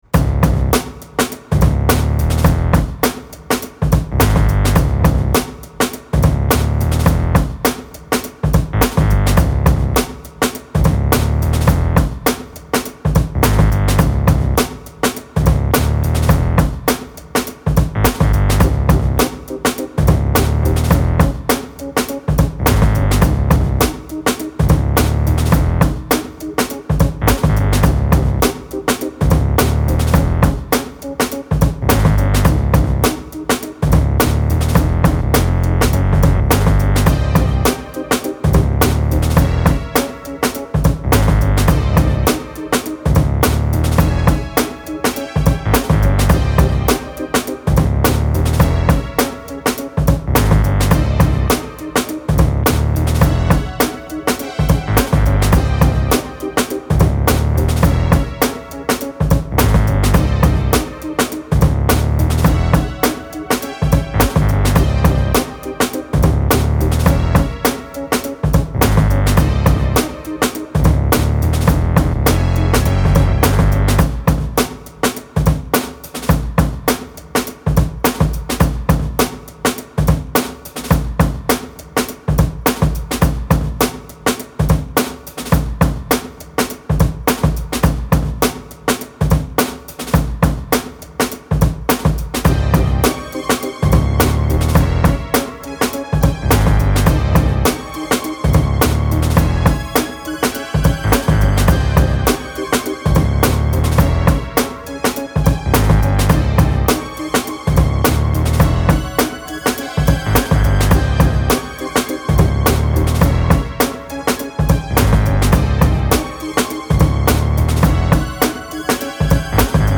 Funky experimental hip hop beat with wacky underscores.